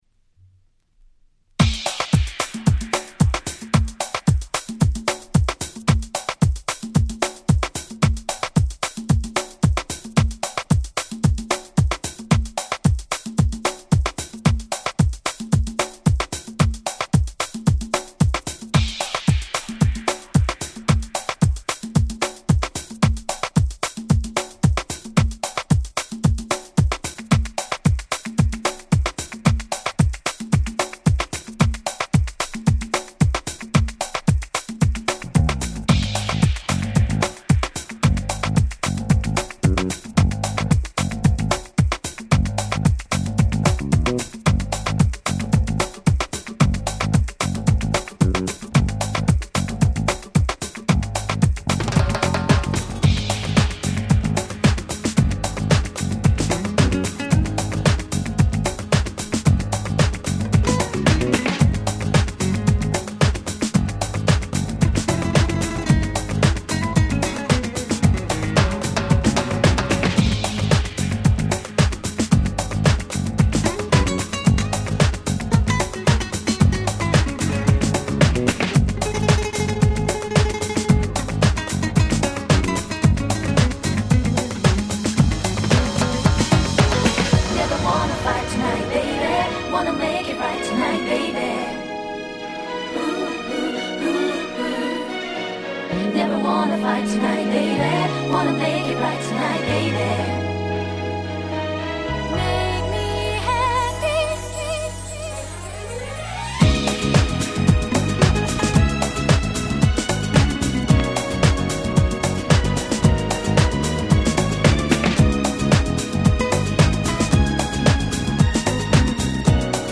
Crossover的なサウンドで大変心地良いっす！